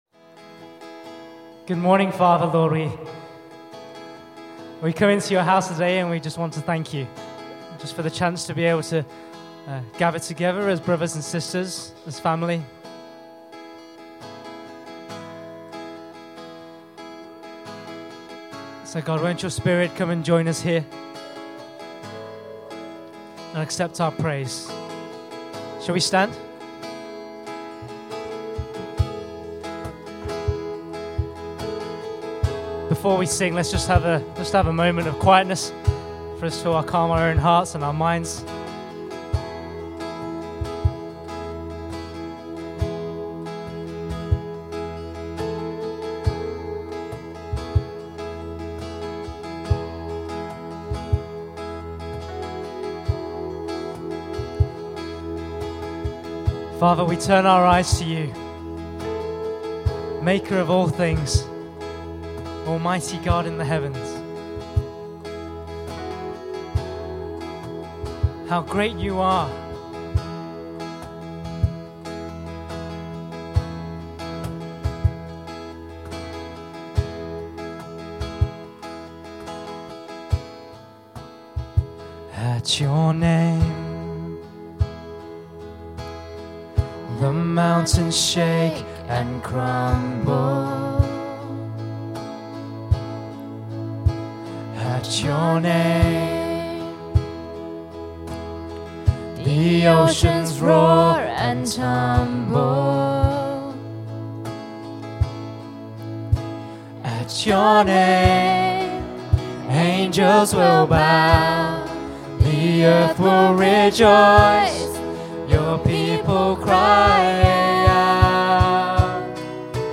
Worship Sets